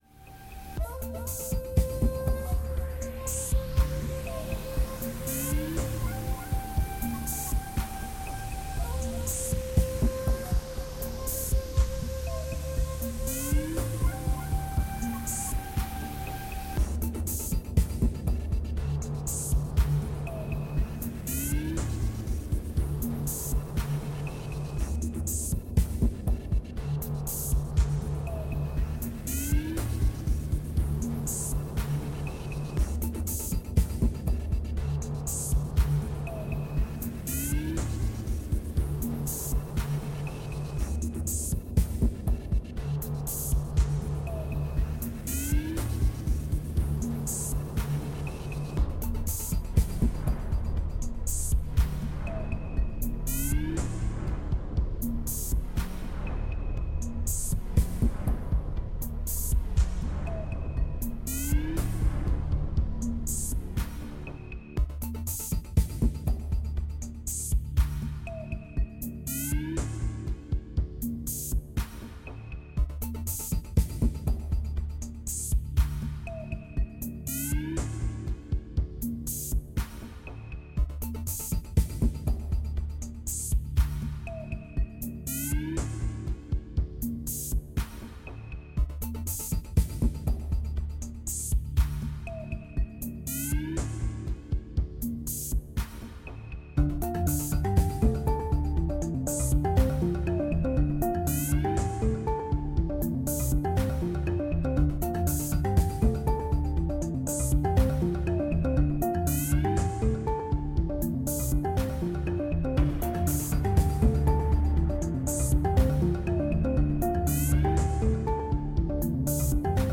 ※音量注意のため、音小さめにレンダリングしてあります
藪のBGM！エルフの声が聞こえてきそうです。
前奏の後、長い長い間奏があるんですが、何と驚きの40小節！！
でも、そのタメにタメた後のかっこいいサビが堪りません。笑